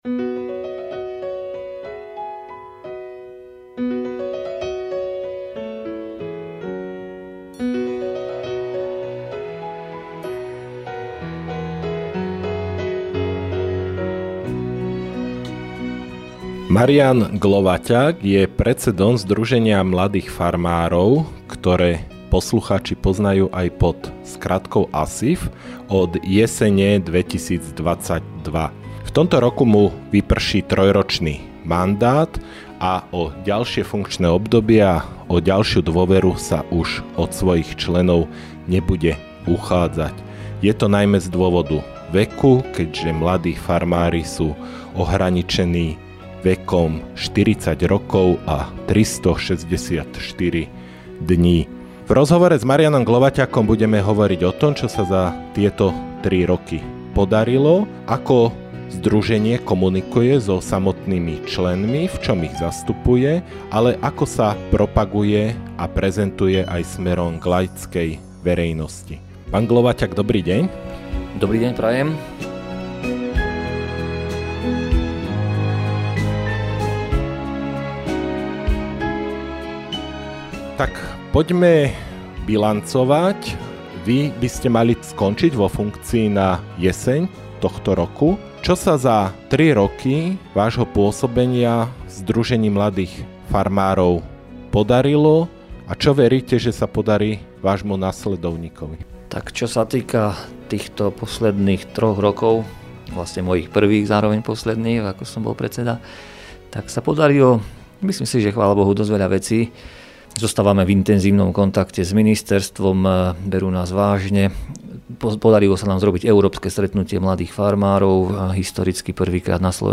V rozhovore